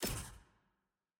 sfx-jfe-ui-roomselect-room-click.ogg